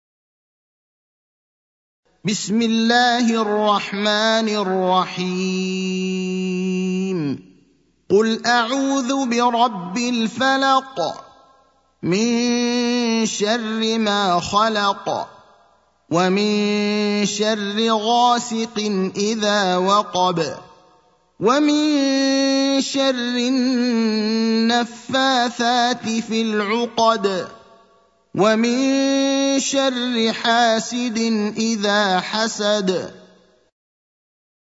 المكان: المسجد النبوي الشيخ: فضيلة الشيخ إبراهيم الأخضر فضيلة الشيخ إبراهيم الأخضر الفلق (113)